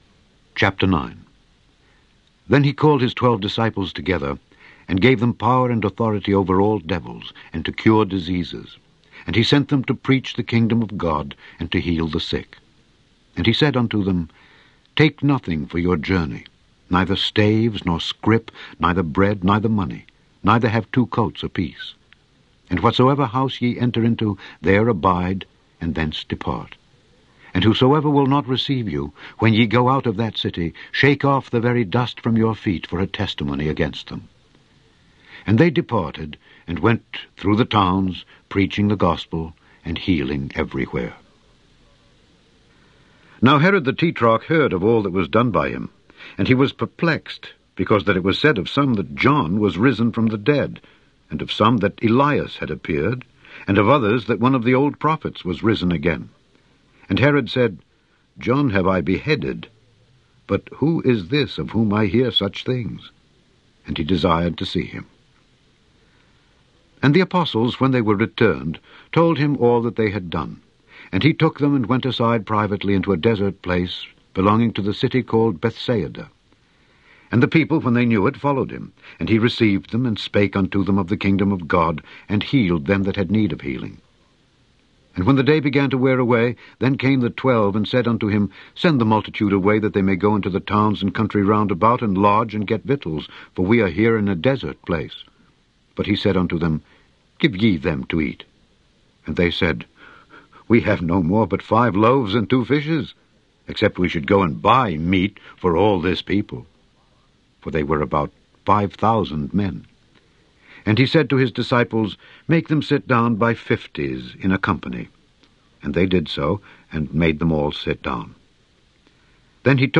In this podcast, you can listen to Alexander Scourby read to you Luke 9-10.